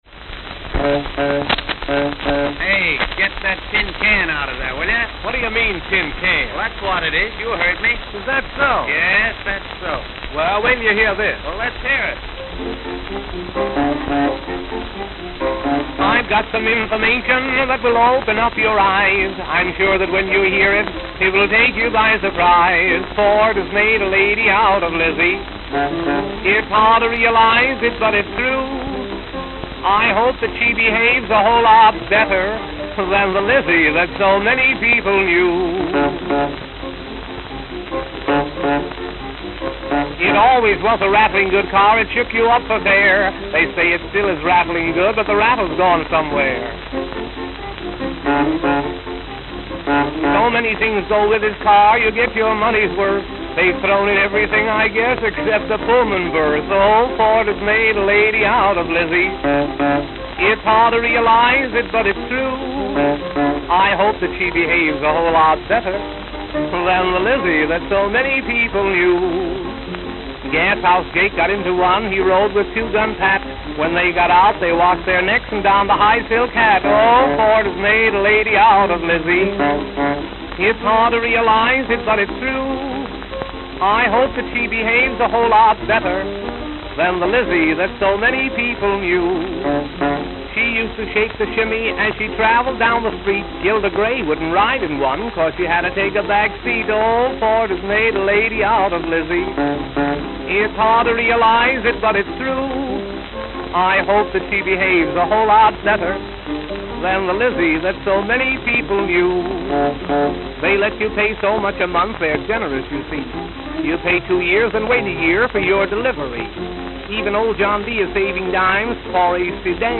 Note: Stripped groove at start. Worn.